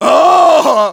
Engineer_painsevere03_de.wav